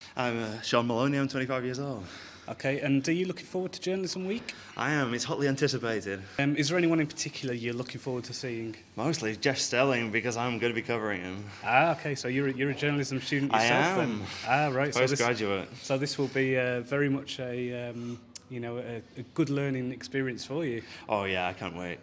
Leeds Trinity University Journalism Week vox pop